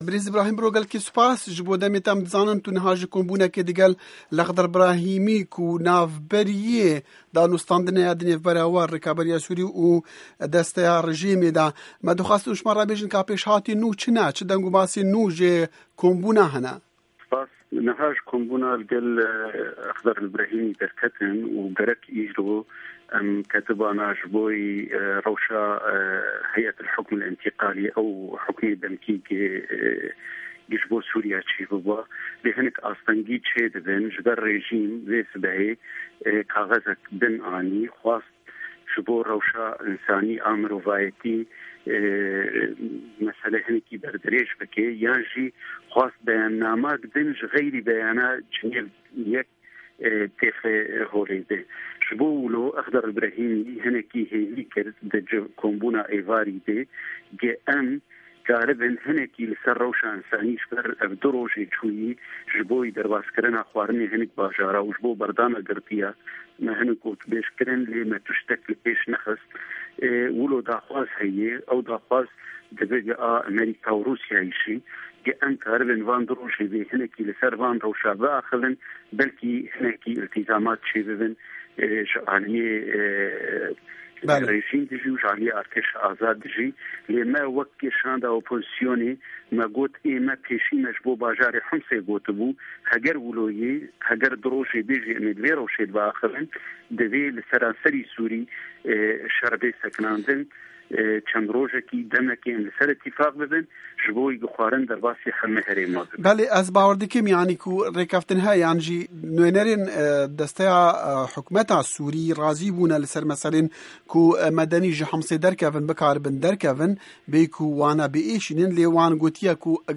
Hevpeyivin